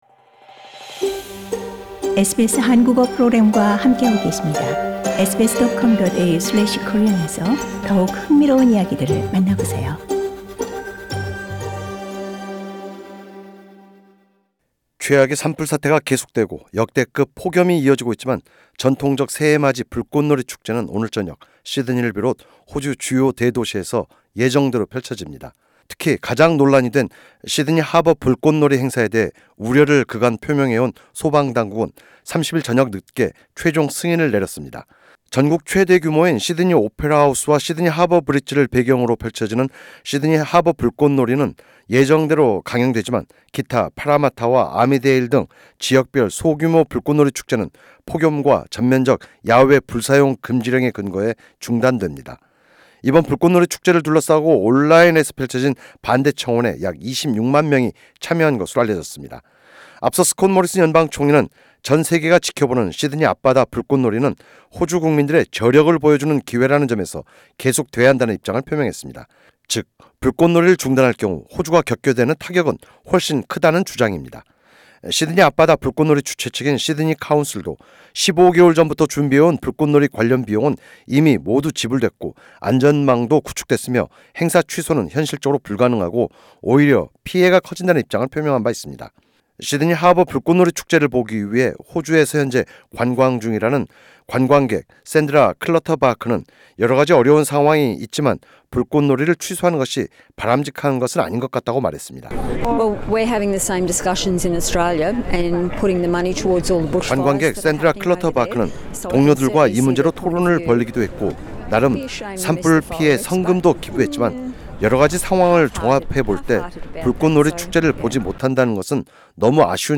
[상단의 팟캐스트를 통해 오디오 뉴스를 접하실 수 있습니다.]